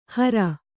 ♪ hara